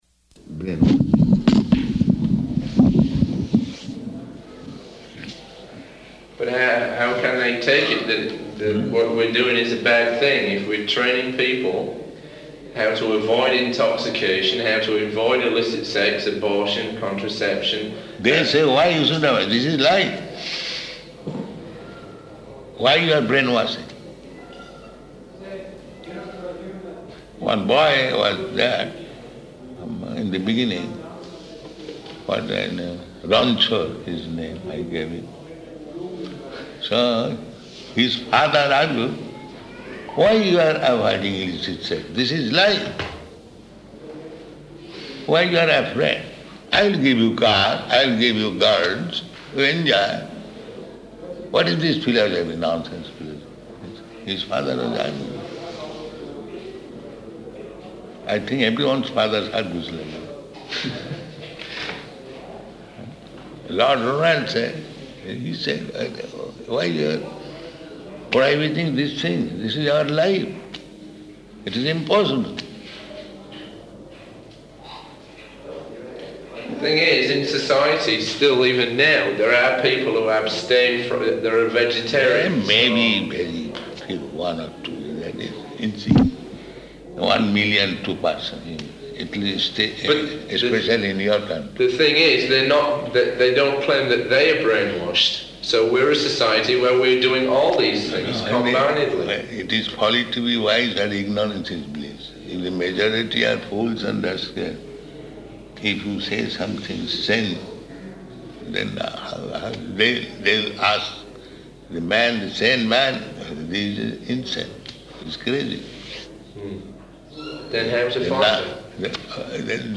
Room Conversation
Type: Conversation